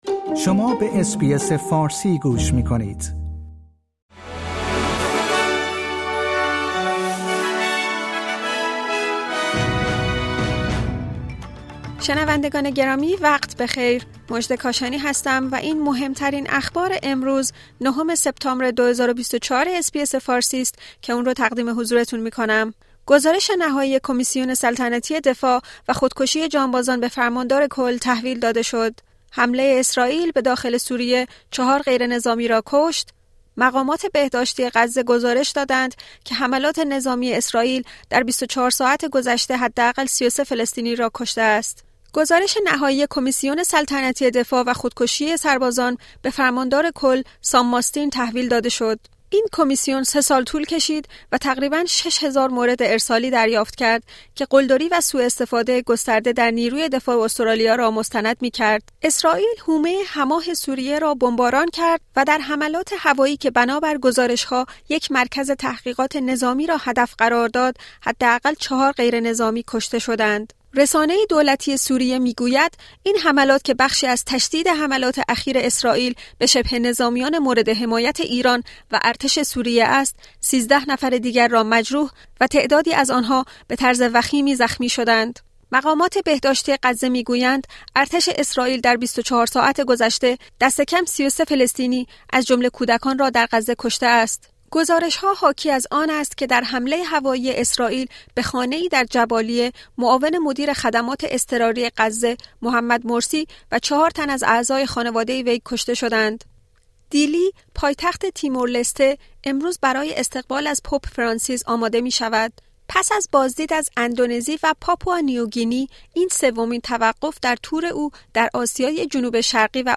در این پادکست خبری مهمترین اخبار استرالیا در روز دوشنبه ۹ سپتامبر ۲۰۲۴ ارائه شده است.